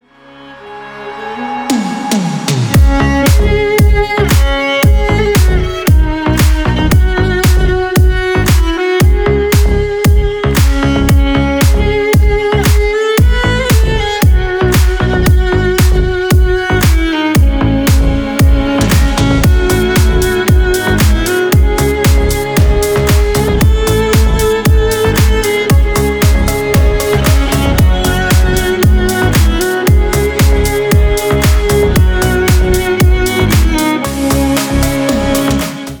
• Качество: 320, Stereo
восточные мотивы
грустные
без слов
скрипка
Стиль: Deep house